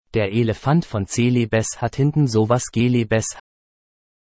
da sind zwar mehrere sprachavatare dabei, die text2speech können und relativ gut klingen.